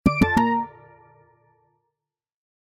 notification_master.ogg